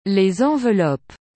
Cette règle entraîne également la modification de la prononciation de certaines lettres : le f se transformera ainsi en v, les s et x se prononceront à la manière d’un z.